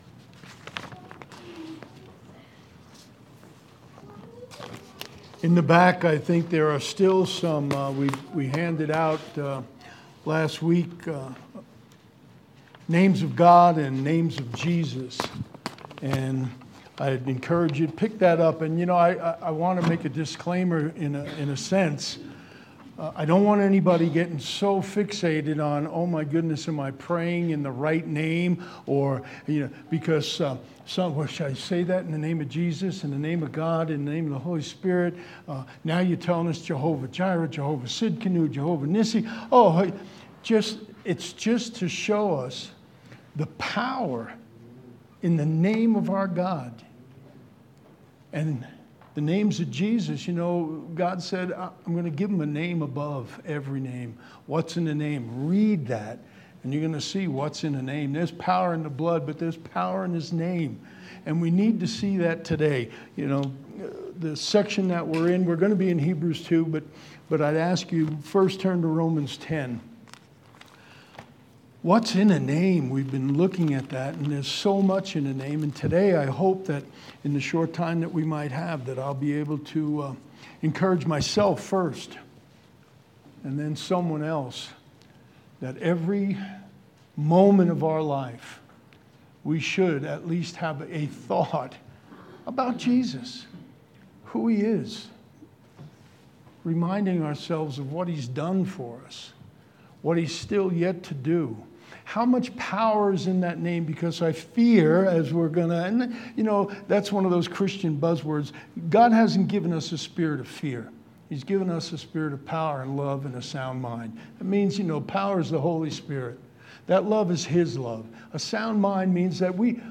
March 13th 2022 Sermon